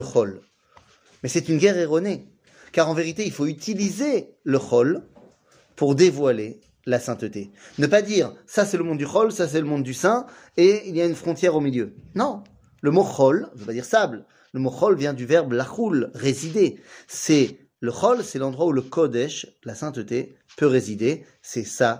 Tania, 58, Chaar Ayihoud Veaemouna, 4 06:12:04 Tania, 58, Chaar Ayihoud Veaemouna, 4 שיעור מ 12 יולי 2023 06H 12MIN הורדה בקובץ אודיו MP3 (344.94 Ko) הורדה בקובץ וידאו MP4 (1.71 Mo) TAGS : שיעורים קצרים